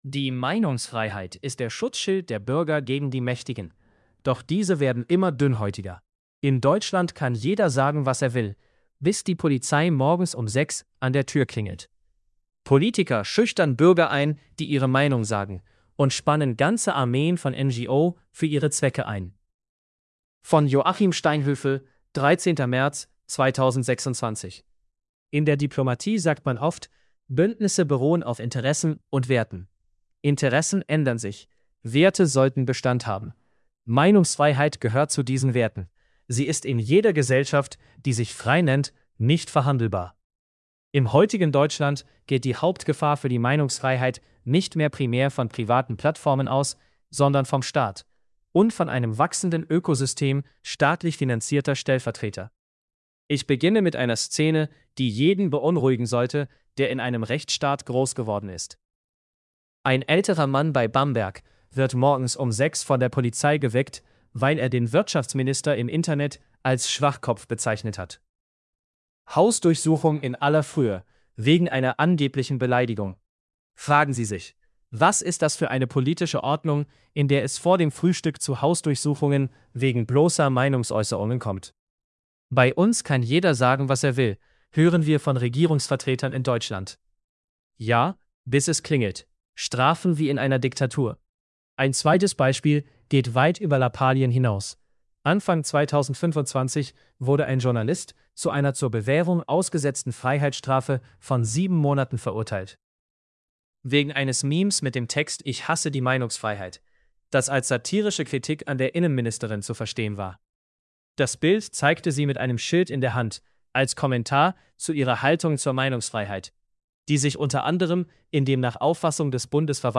joaquin_male.mp3